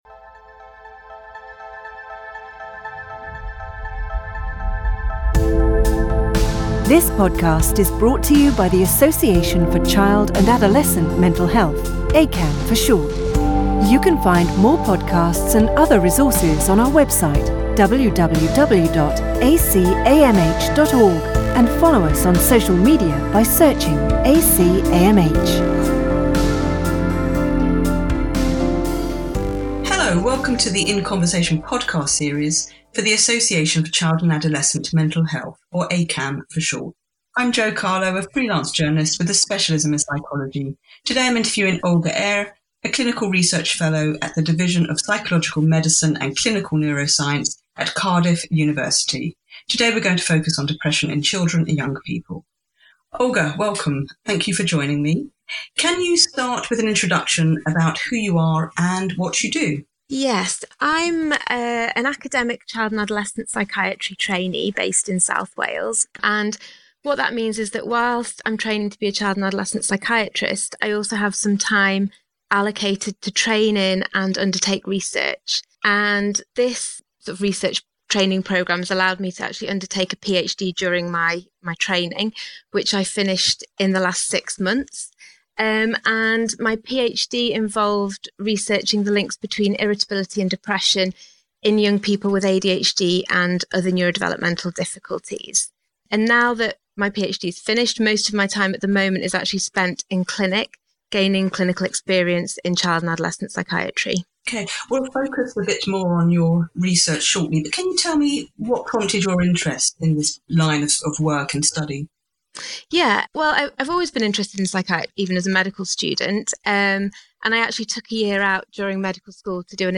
In Conversation...